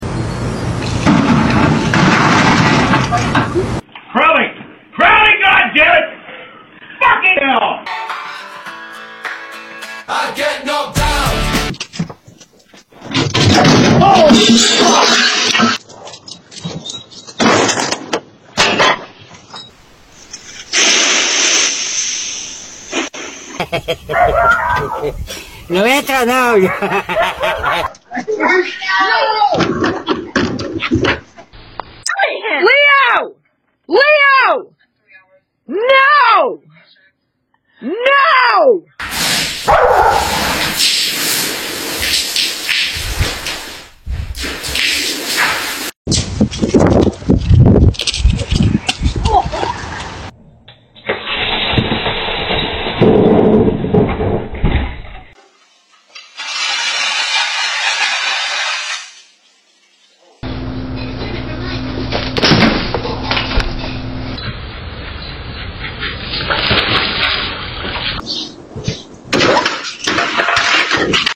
Dogs caught on security camera sound effects free download